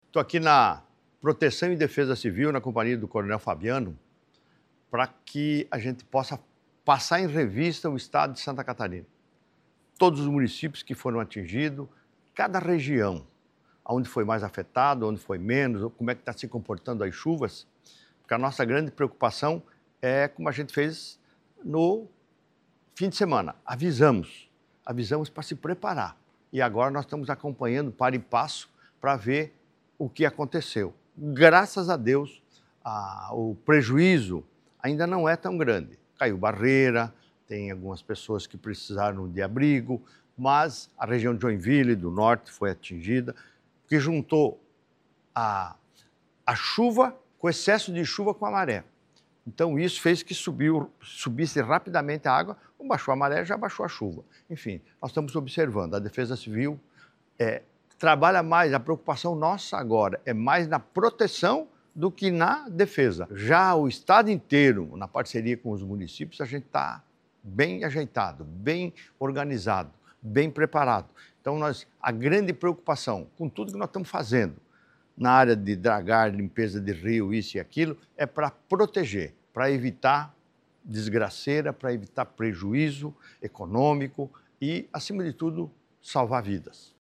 O governador Jorginho Mello esteve na Defesa Civil do Estado acompanhando as ocorrências:
SECOM-Sonora-governador-Jorginho-Mello-chuvas-em-SC.mp3